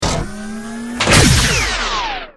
battlesuit_hugelaser.ogg